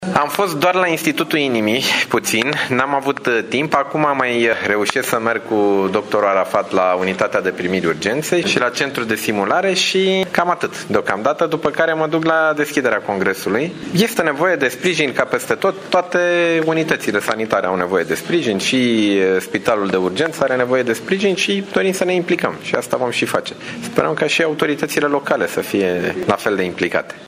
Ministrul Sănătății, Nicolae Bănicioiu: